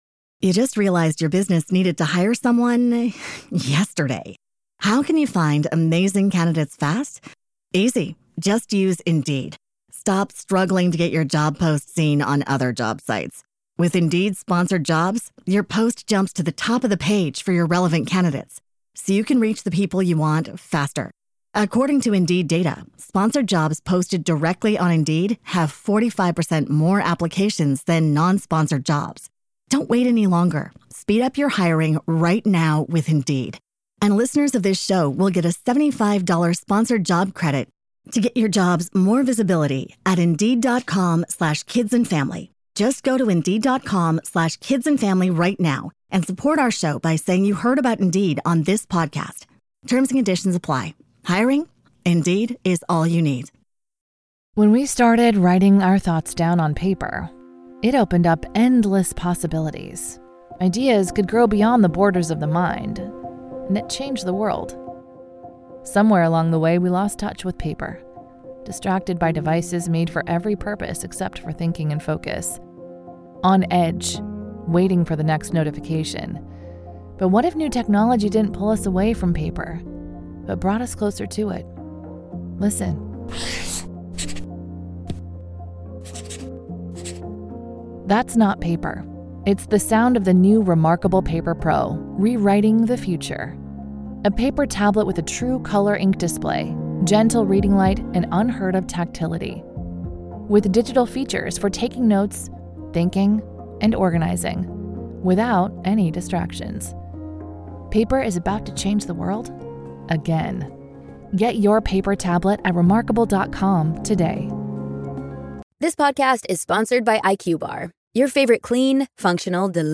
To listen to part two of our interview, you need to be a Grave Keeper (supporter of the show).